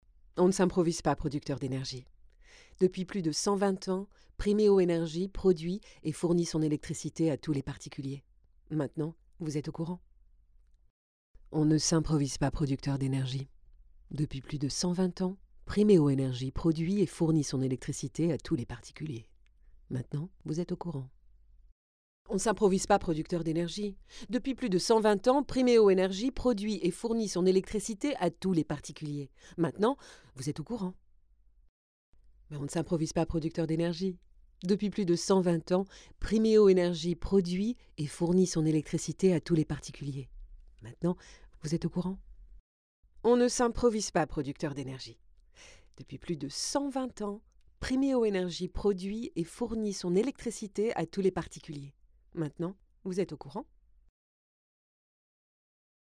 Voix off
27 - 72 ans - Mezzo-soprano